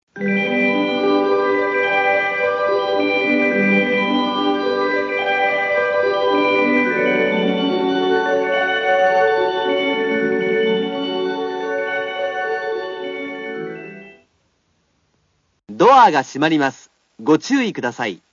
USO生
suidoubashi2.wav